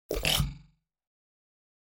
جلوه های صوتی
دانلود صدای ربات 75 از ساعد نیوز با لینک مستقیم و کیفیت بالا